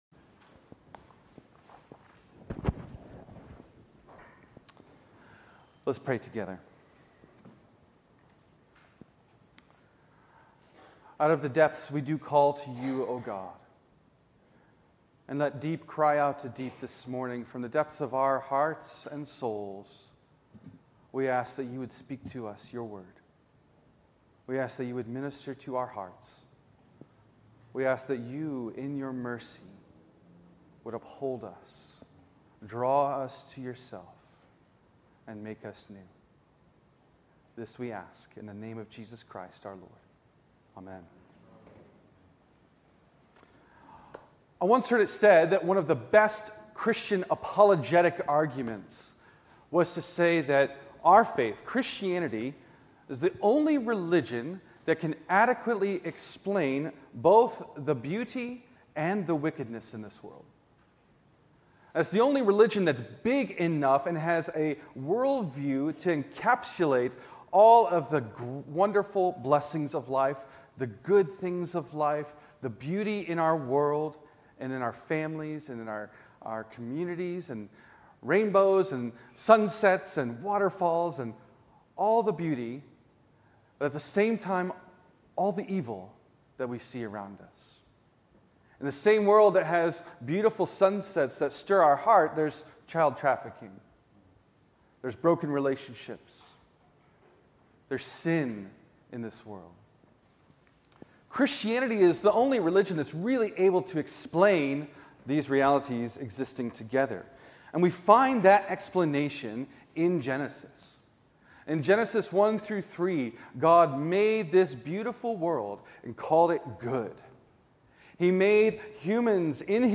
Fall and Response - St. Andrew's Anglican Church